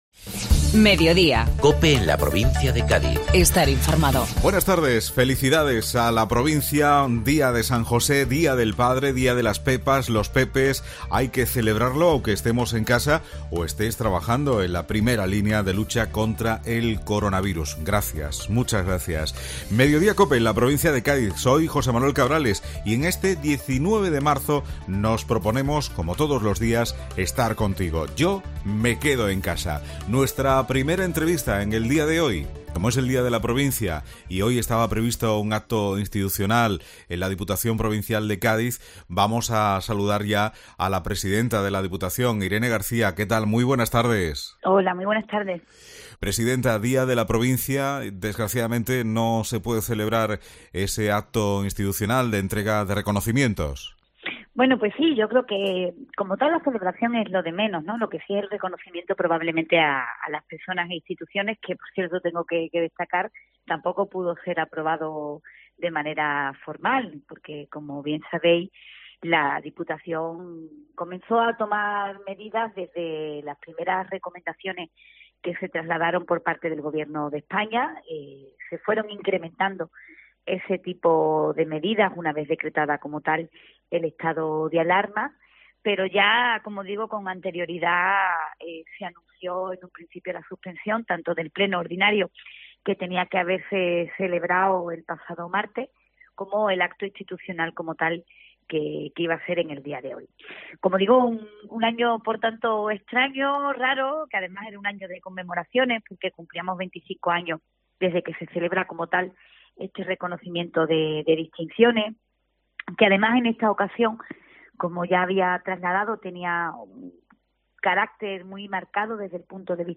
La presidenta de Diputación, Irene García, se dirige a todos los gaditanos en el Día de la Provincia de Cádiz